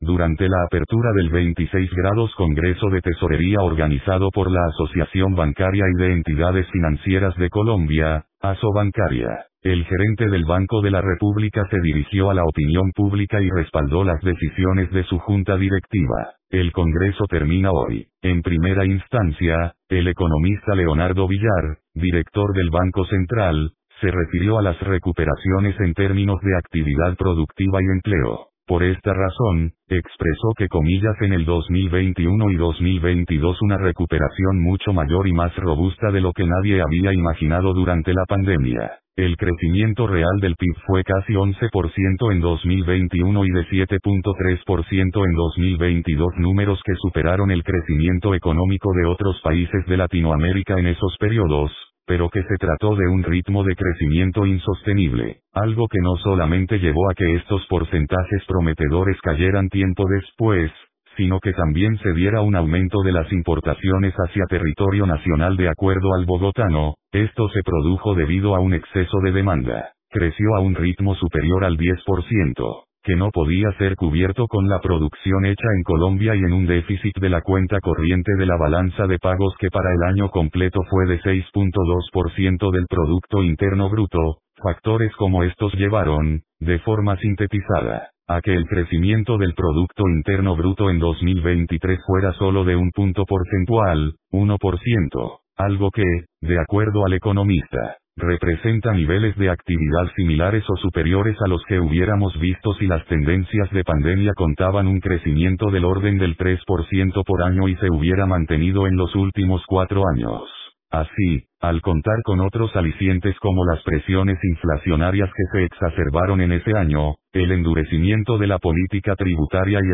Durante la apertura del 26° Congreso de Tesorería organizado por la Asociación Bancaria y de Entidades Financieras de Colombia (ASOBANCARIA), el gerente del Banco de la República se dirigió a la opinión pública y respaldó las decisiones de su junta directiva.
Leonardo Villar, director del Banco de la República.
Todo, durante este evento organizado por Asobancaria en el Gran Salón Bolívar del Hotel Hilton Cartagena.